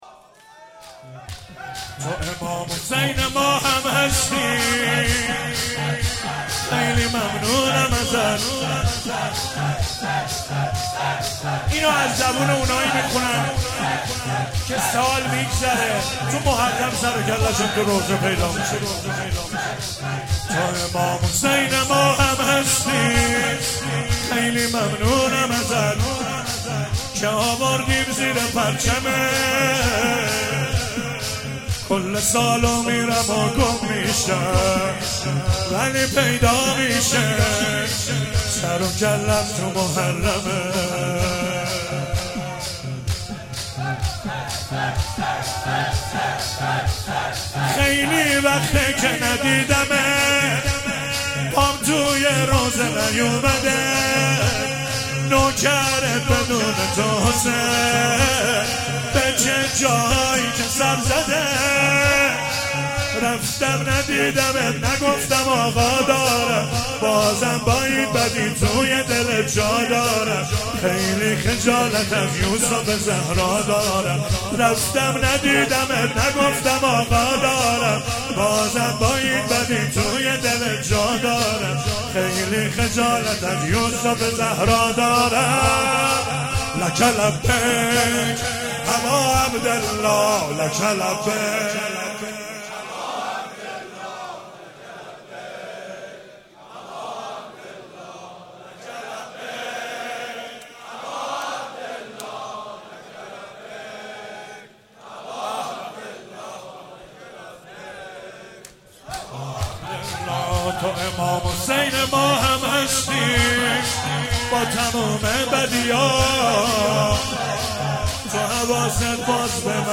مداحی جدید
شور